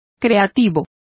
Complete with pronunciation of the translation of creative.